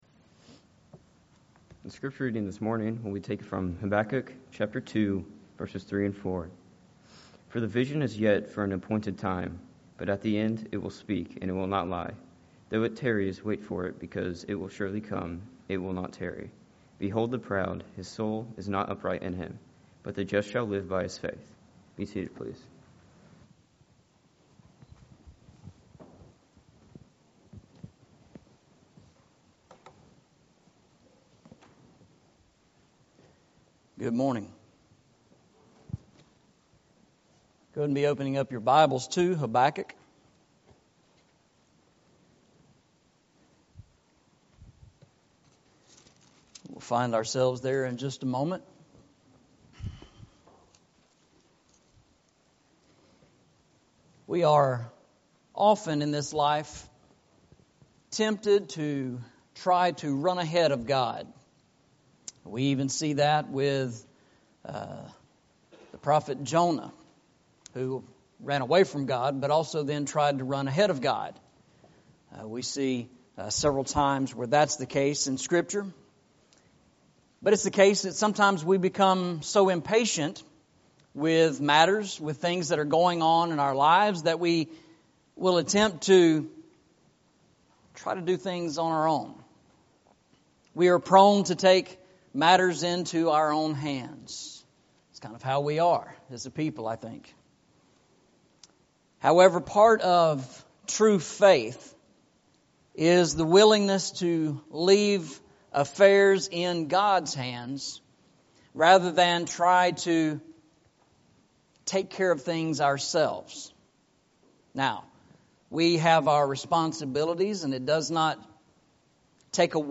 Eastside Sermons Passage: Habakkuk 2:3-4 Service Type: Sunday Morning « The Smitten Rock